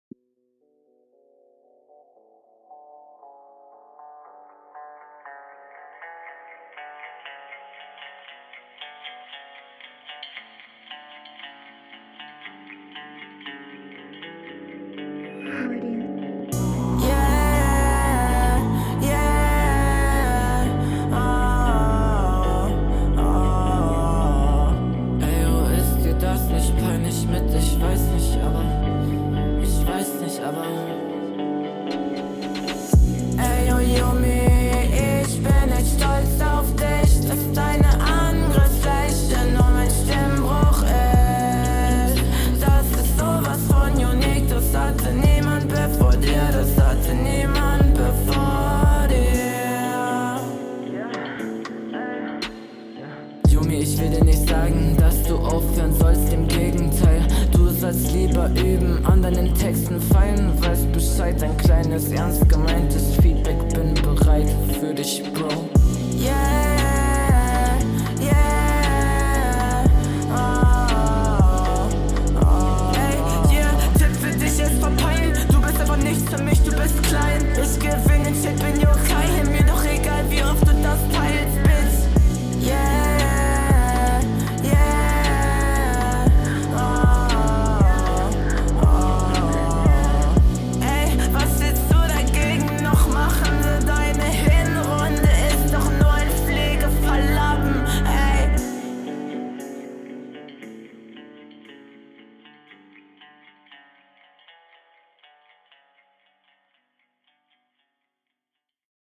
Ok das klingt super gut, super Stimmeinsatz, auch schön Emotionen in der Stimme, das passt …
Flow: Mir persönlich schon wieder zu viel Sing Sang.